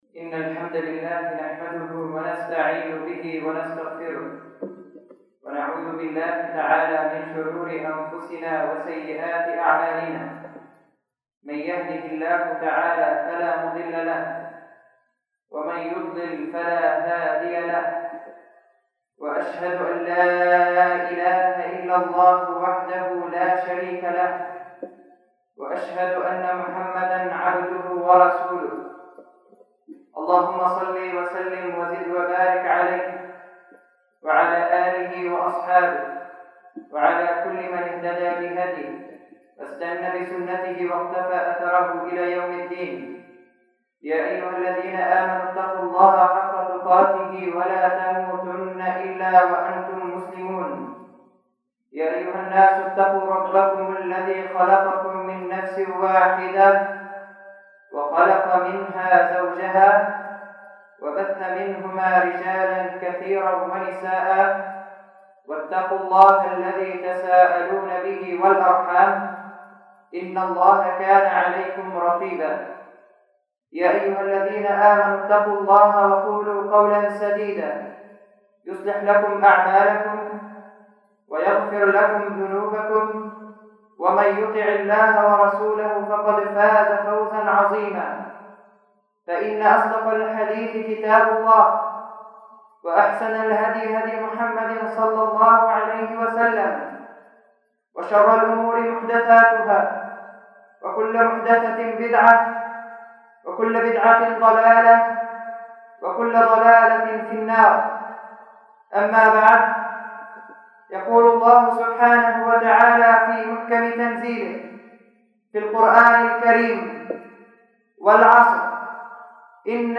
[خطبة جمعة] العصر
المكان: مسجد إيزال-الضنية الموضوع: العصر تحميل